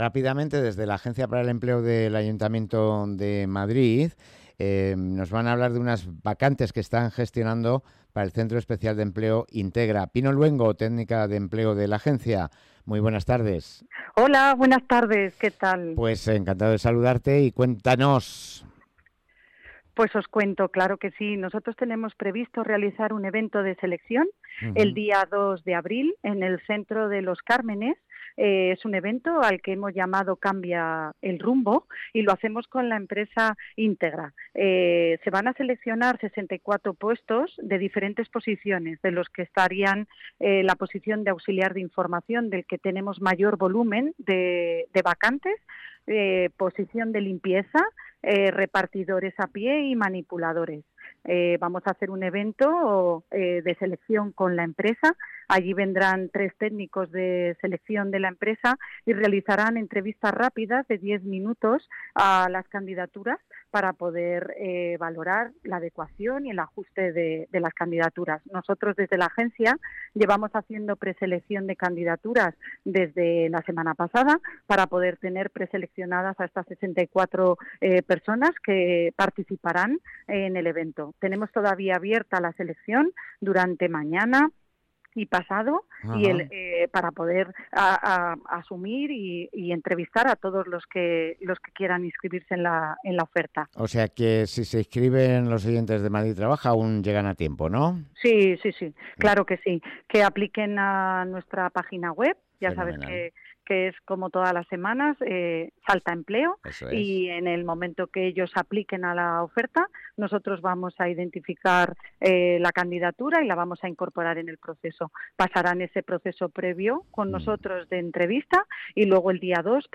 Entrevista a María Estévez, concejala de Hacienda y RRHH del Ayuntamiento de Leganés